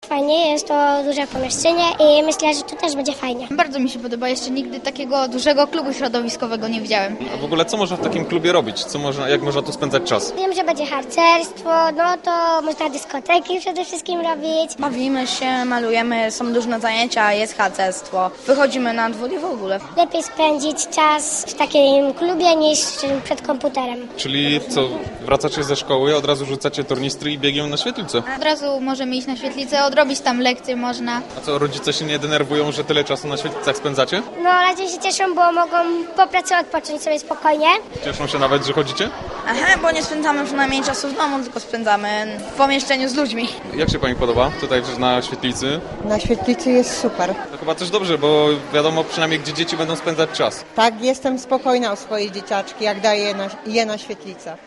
Mieszkańcy Żarkowa i pobliskiego Piastowa twierdzili, że stworzenie klubu w tym miejscu to dobry pomysł.